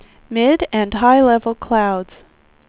speech / tts / prompts / voices